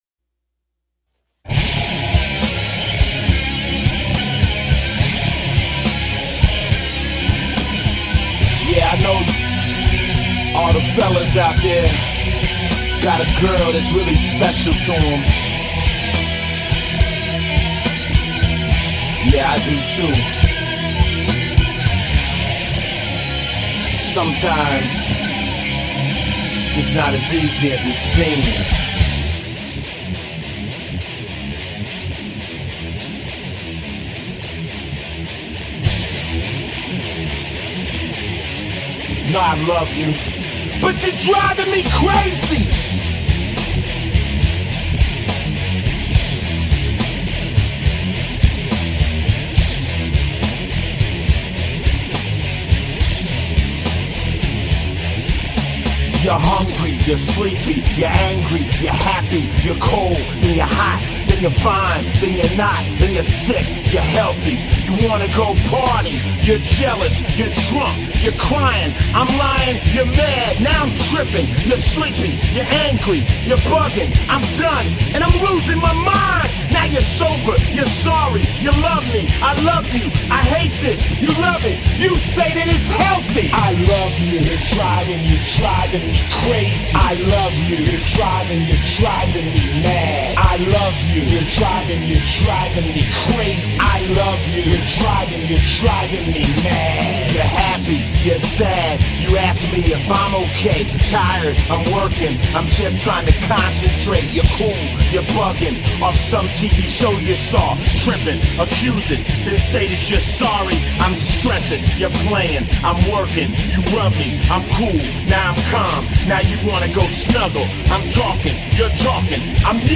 THE ORIGINAL GHETTO METAL GROUP IS BACK WITH A VENGENGANCE!!